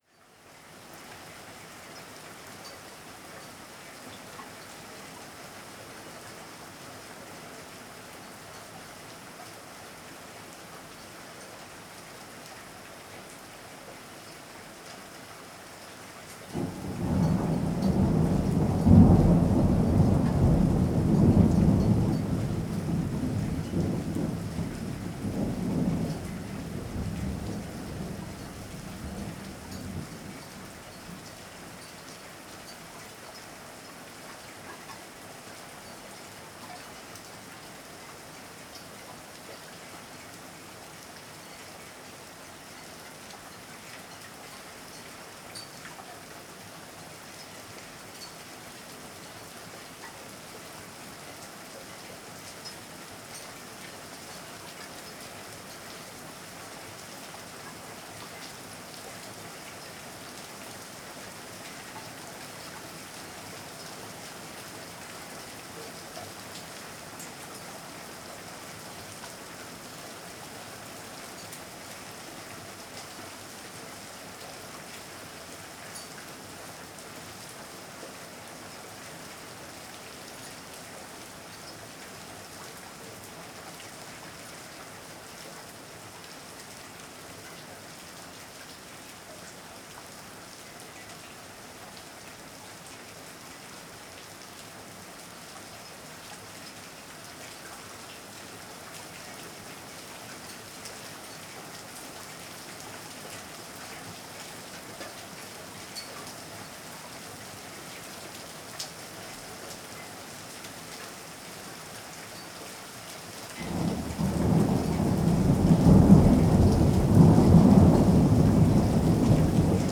16:12 MP3 In the land of Aragon, recordings from the hill of the ‚Casa del Poeta‘ in the village of Trasmoz. Land of winds, black cats, griffons and witchcrafts, every night a shepherd enters his flocks.